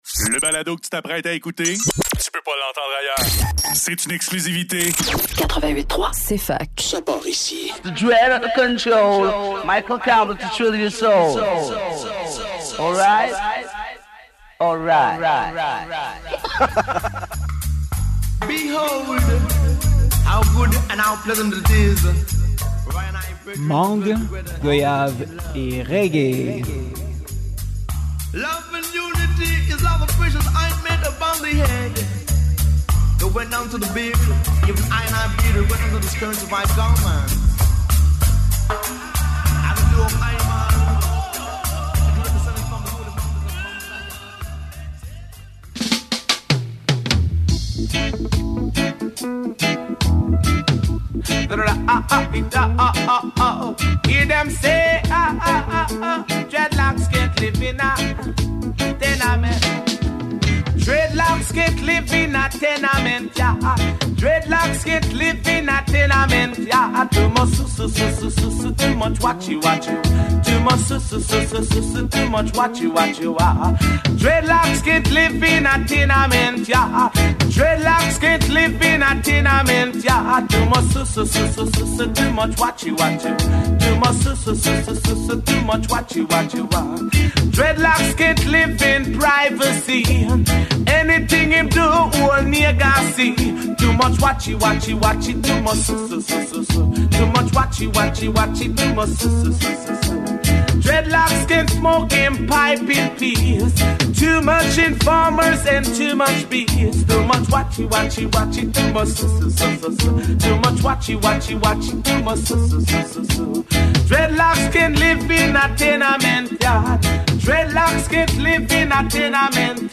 Roots Reggae